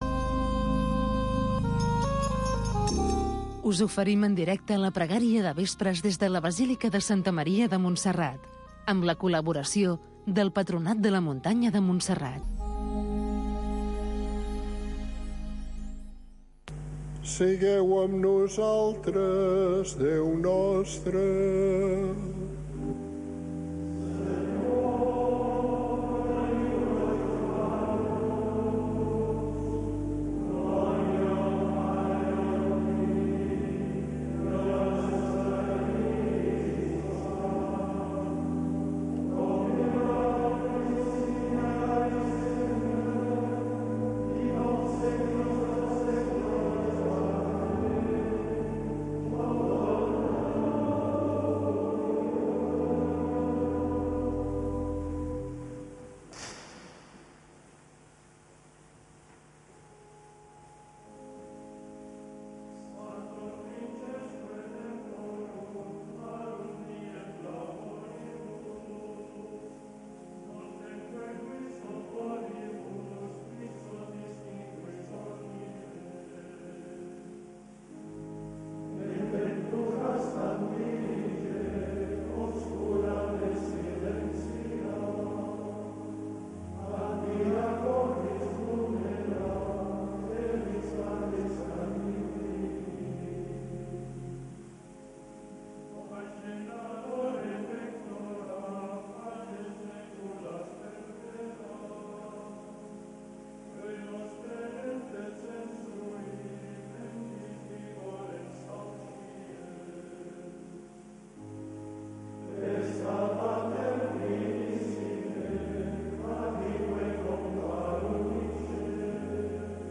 Pregària del vespre per donar gràcies al Senyor. Amb els monjos de Montserrat, cada dia a les 18.45 h